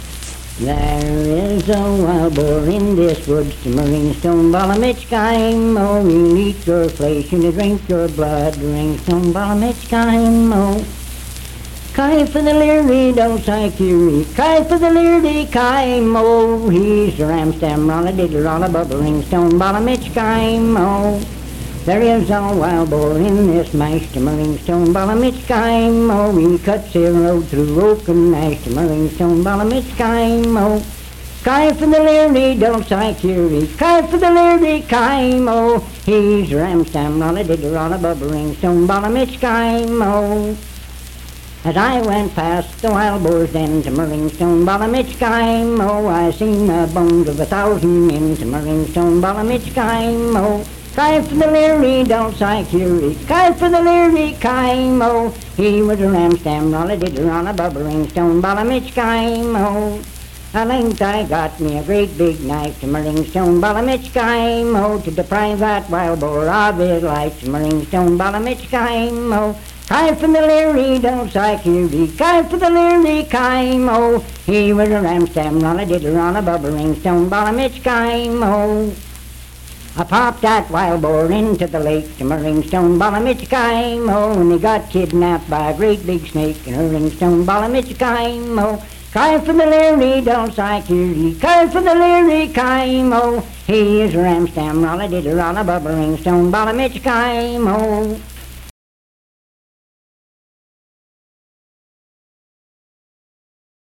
Unaccompanied vocal music
Performed in Sandyville, Jackson County, WV.
Voice (sung)